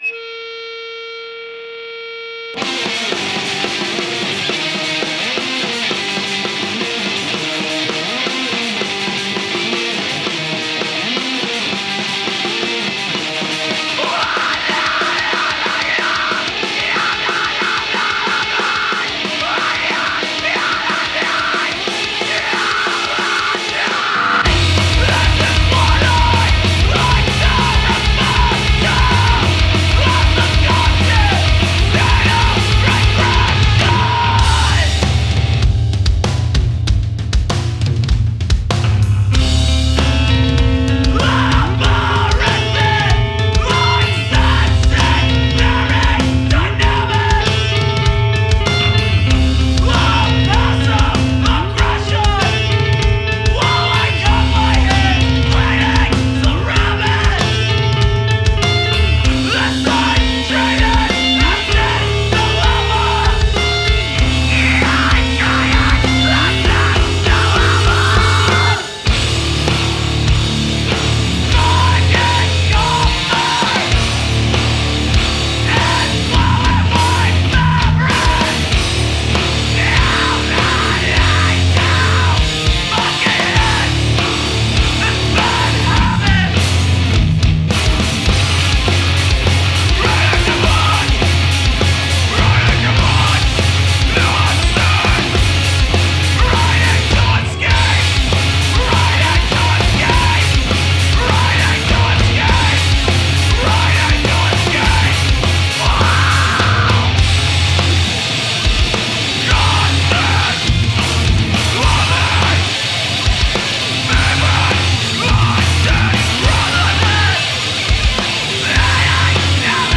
emo See all items with this value
Punk Rock Music